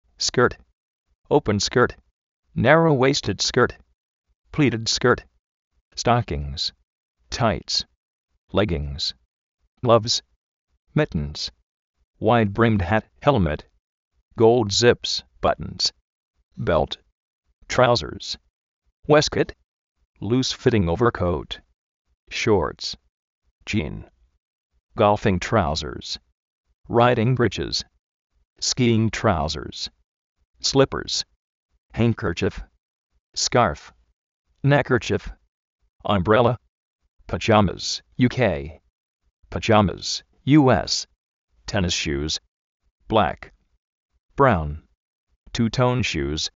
skért, óupem skért,
stókins, táits
glóvs, mítens
uéistkout
piyámas (UK), payámas (US)